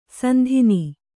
♪ sandhini